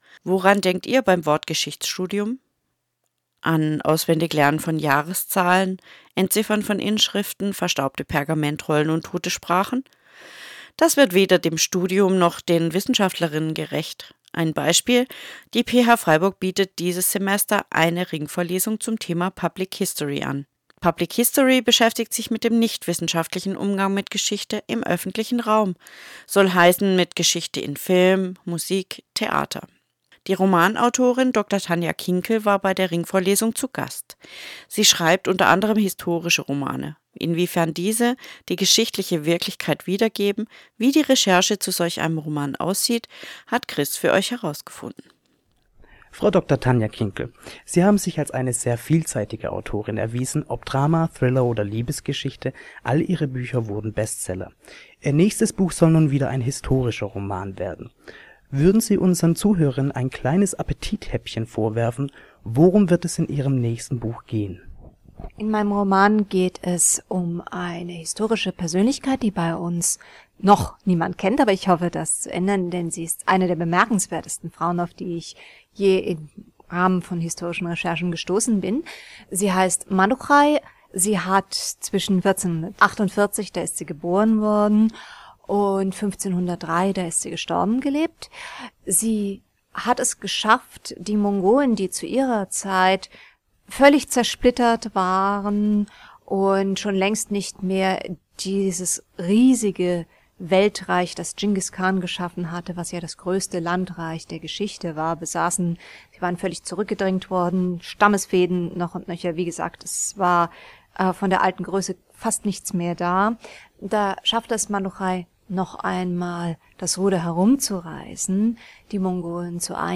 Romanautorin Dr. Tanja Kinkel im Gespräch mit PH 88,4 – Podcastarchiv der PH-Freiburg
romanautorin-dr-tanja-kinkel-im-gespraech-mit-ph-884.mp3